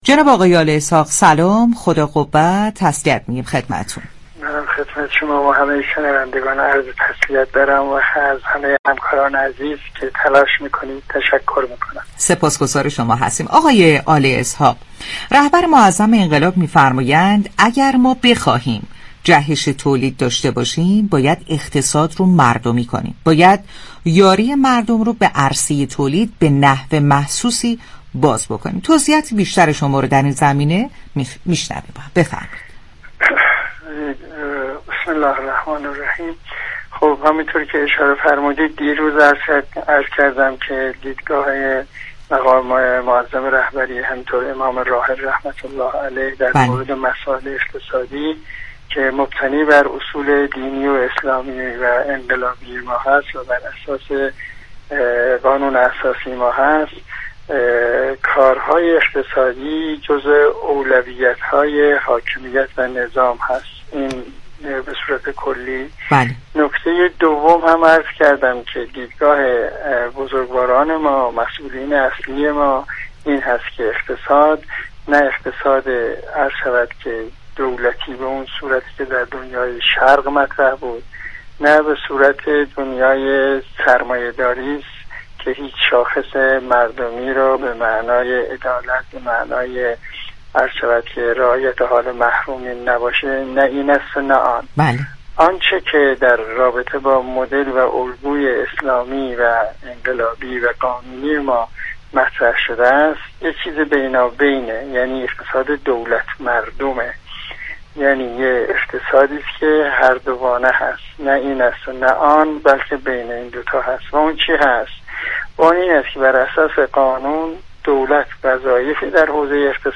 به گزارش پایگاه اطلاع رسانی رادیو تهران، یحیی آل اسحاق رئیس اتاق بازرگانی مشترك ایران و عراق در گفت و گو با ویژه برنامه «امام امت» كه ویژه روزهای 14 و 15 خردادماه بر روی آنتن رادیو تهران رفت، اظهار داشت: اقتصاد جزو اولویت‌های حاكمیت و نظام است.